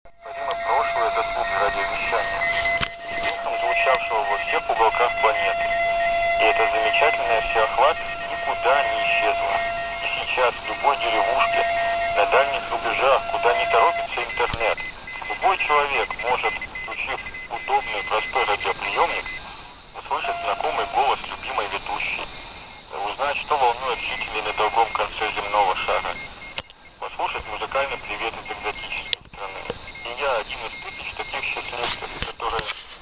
Сегодня на 14 мГц в SSB участке на ТПП прослушивалось несколько станций.
Пожалуйста: запись помех от радиовещательной станции.